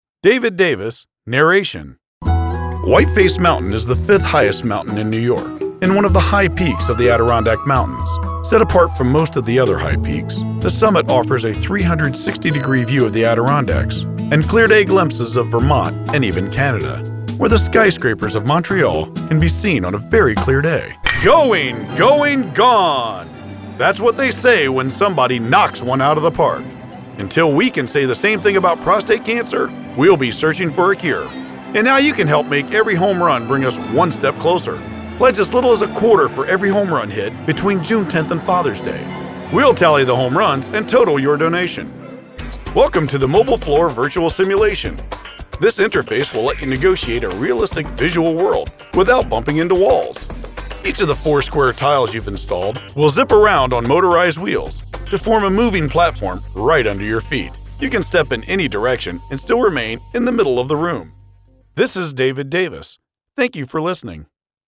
From calm and soothing to outrageous and zany, I can tailor my voice and delivery to embrace a wide range of scenarios.
That is why I will provide a finished product that you can be proud of using my personal, professional studio.